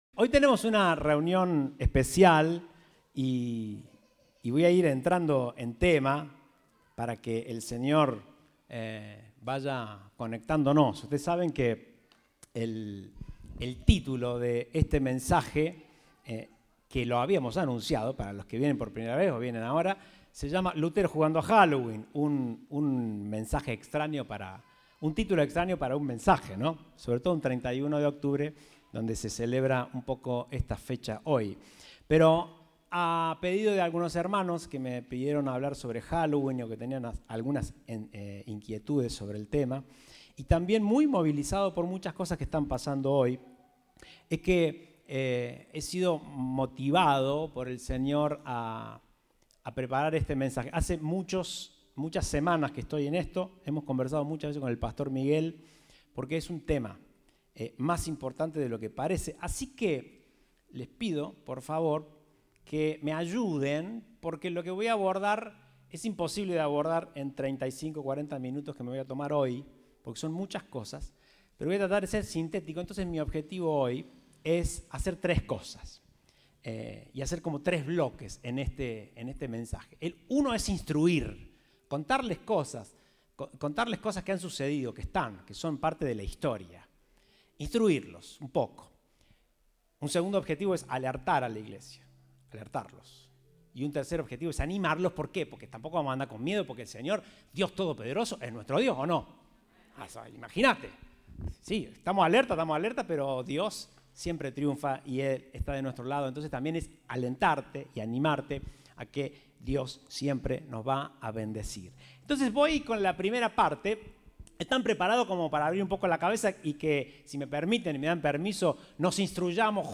Compartimos el mensaje del Domingo 31 de Octubre de 2021.